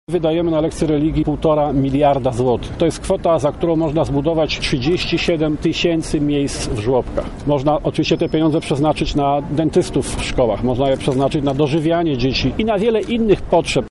– Najważniejszą kwestią jest wyprowadzenie religii ze szkół – mówi Janusz Palikot ze Zjednoczonej Lewicy